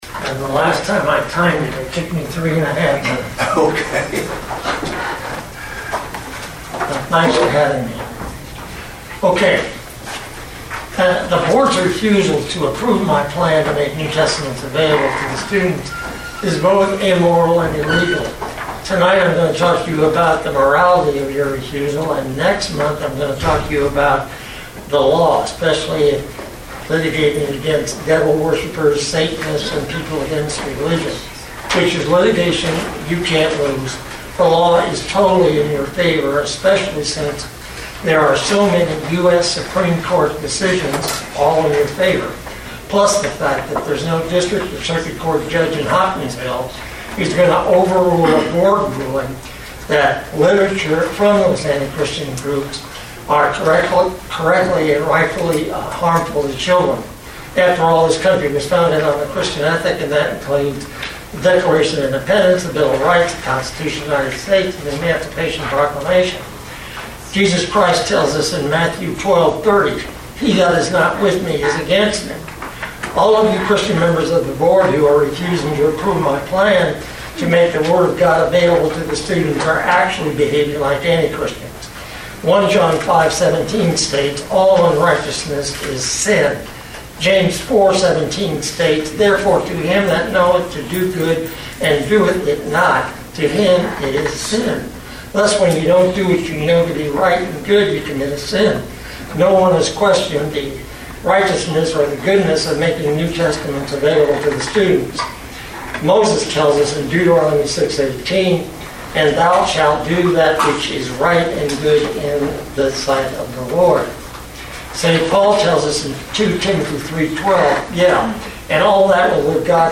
In a three-minute sermon to board members